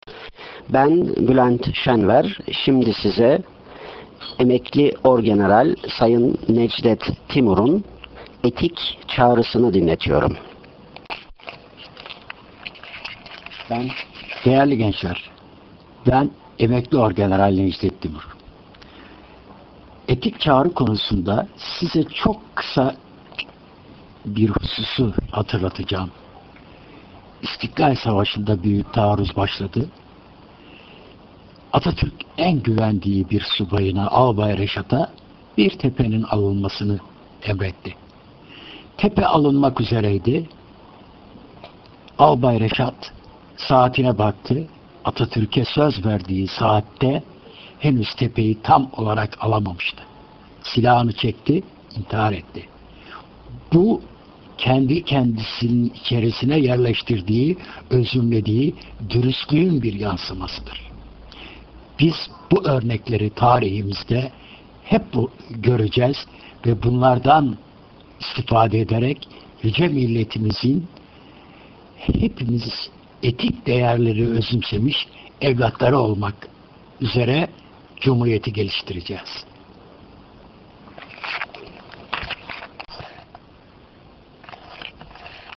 Kendi Sesiyle